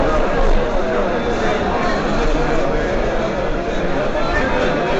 Crowd Murmur
A steady background murmur of a medium-sized crowd in conversation at a social event
crowd-murmur.mp3